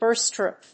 アクセント・音節búrst・pròof